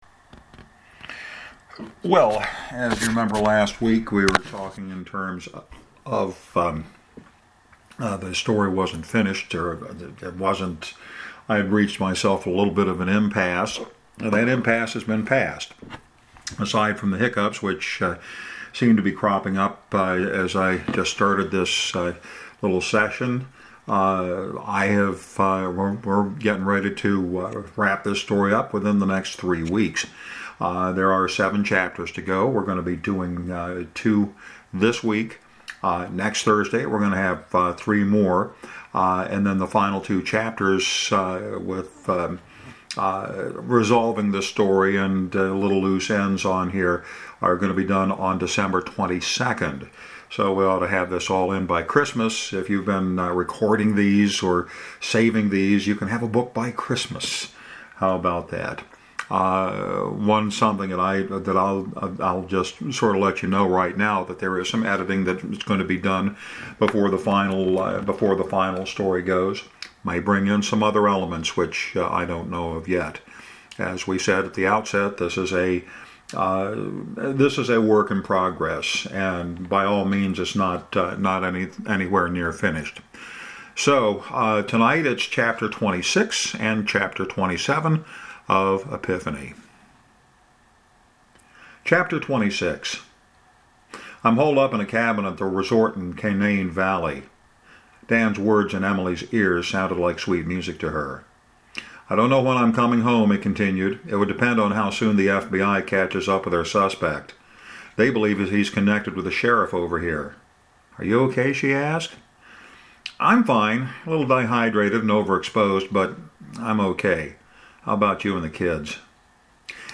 As I will explain on this week’s reading, Epiphany is finished (first draft, mind you) with just a handful of chapters left to go. This week, two chapters, dealing with Emily’s side of the story.